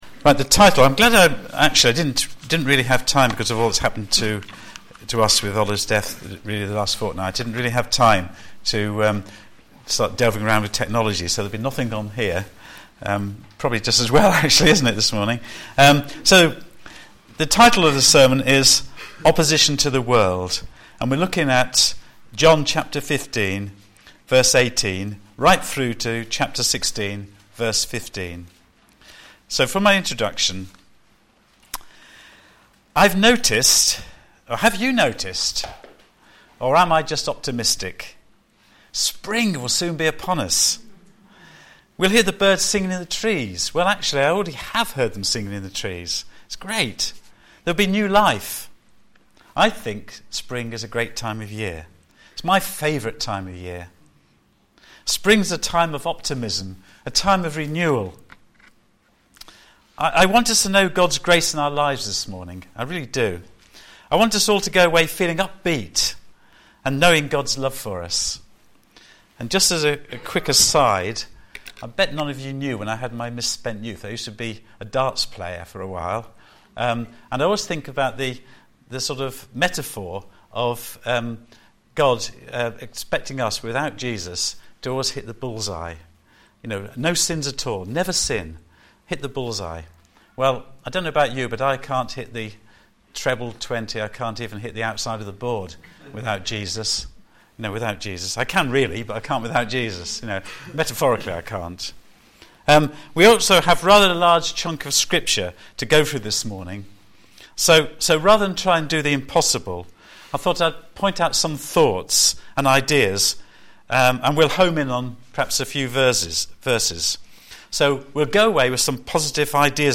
Media for a.m. Service on Sun 15th Feb 2015 10:30
Series: John on Jesus Theme: Opposition to the World Sermon